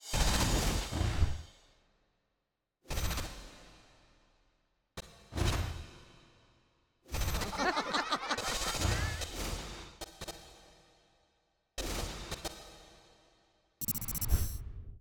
EHC_PARK_COMBO_DT15_ST_SFX.wav